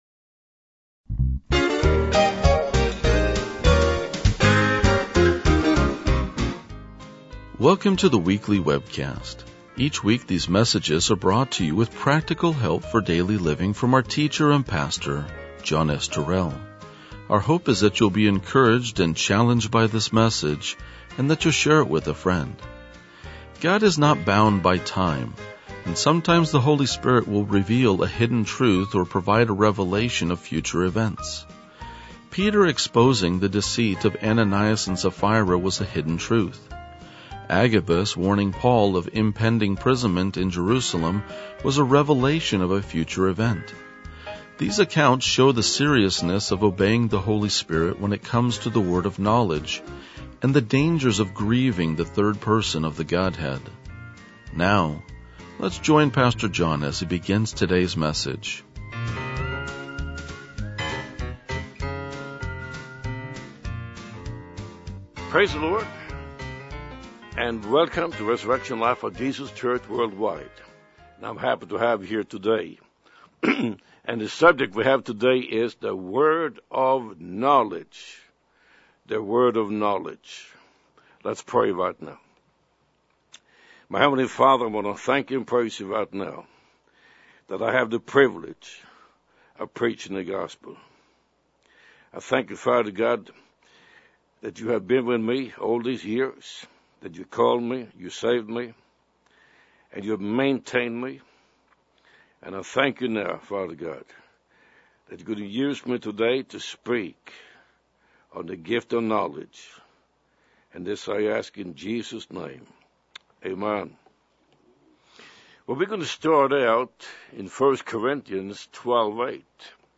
RLJ-2023-Sermon.mp3